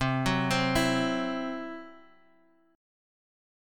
CMb5 Chord